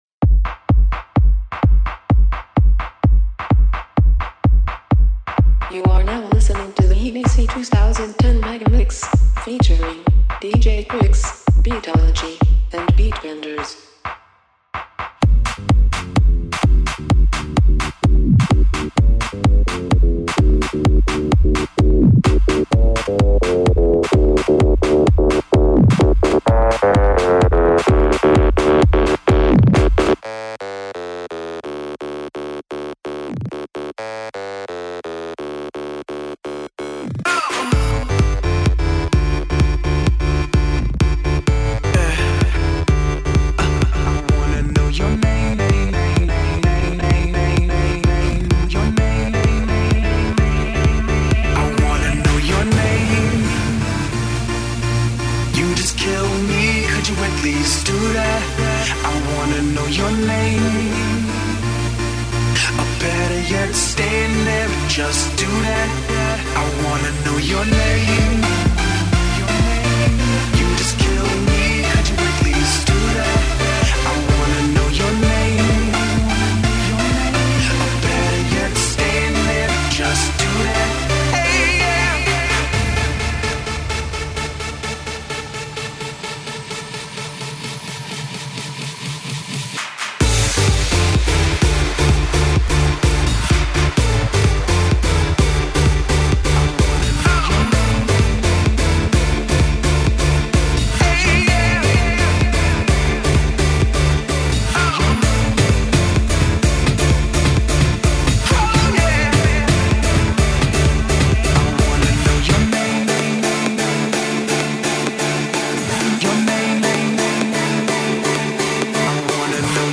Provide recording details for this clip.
live mix